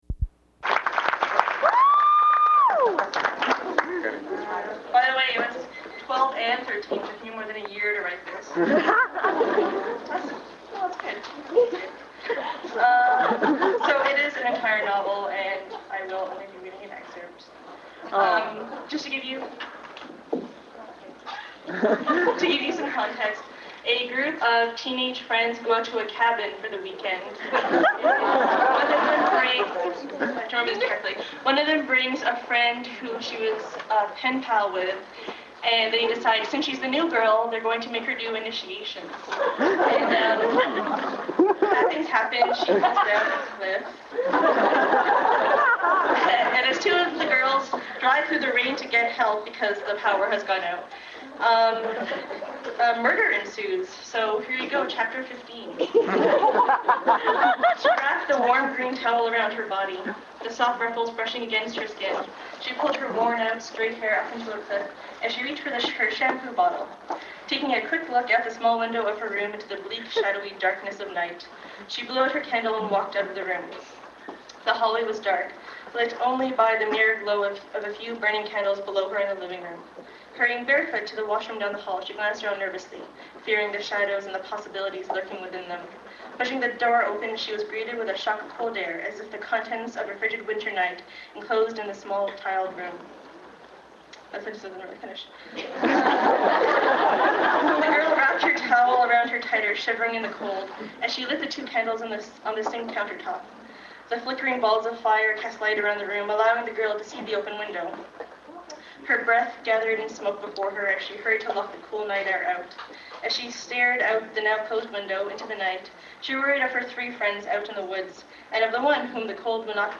Ten years later, I read a chapter from it at a session of Grownups Read Things They Wrote as Kids.